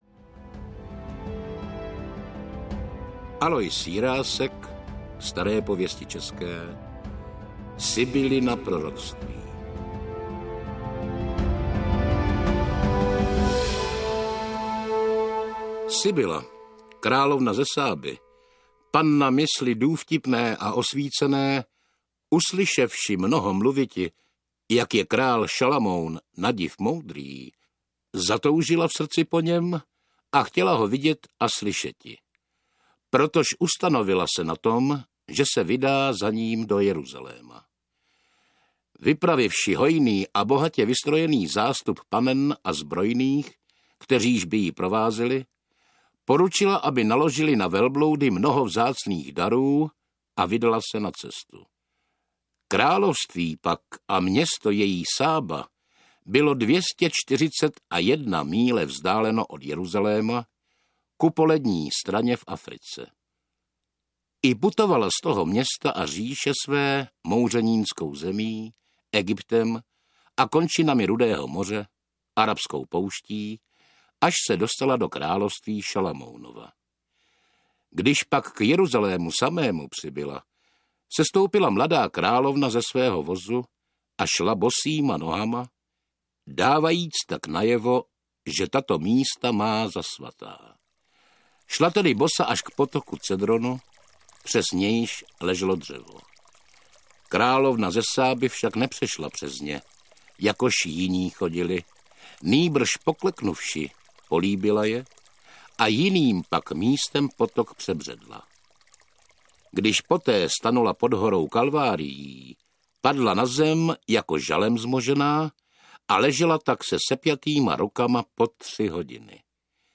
Ze starobylých proroctví audiokniha
Audiokniha Ze starobylých proroctví ze Starých pověstí českých od českého klasika Aloise Jiráska s mistry slova Norbertem Lichým a Zdeňkem Maryškou.
Ukázka z knihy